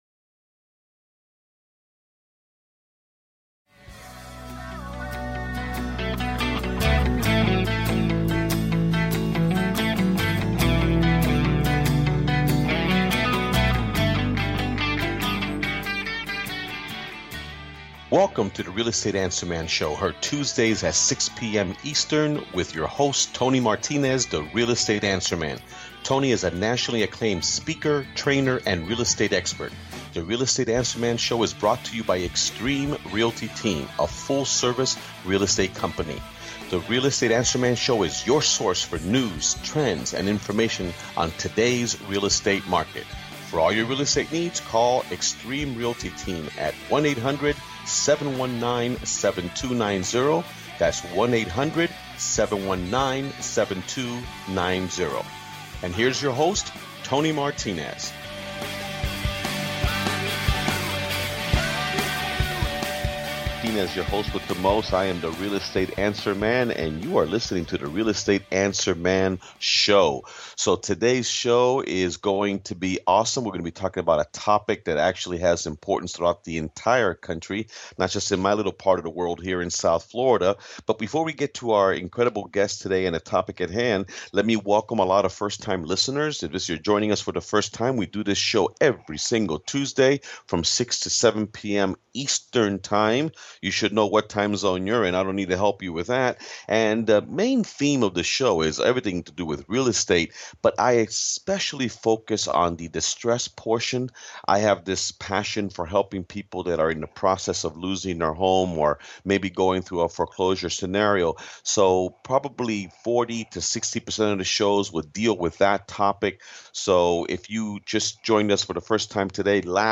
The Real Estate Answer Man show offers straight talk, news and commentary on today’s real estate market. All facets of our dynamic real estate market will be covered. Industry experts join the host to discuss: